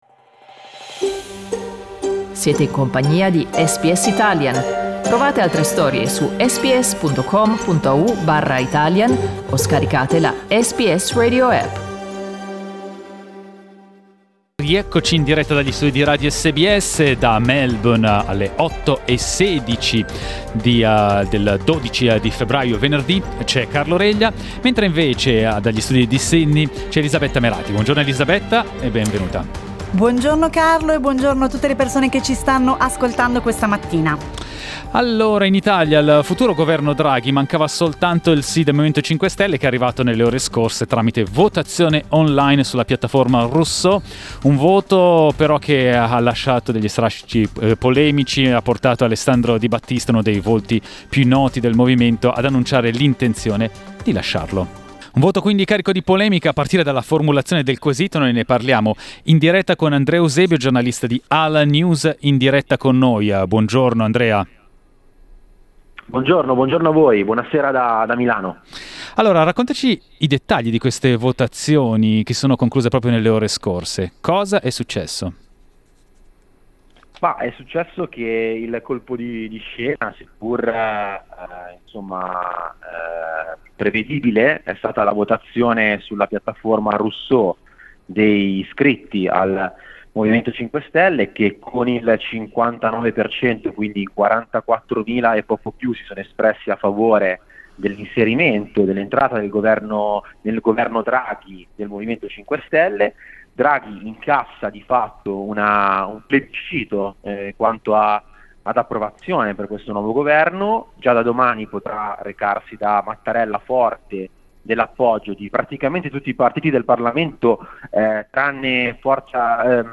SBS in Italiano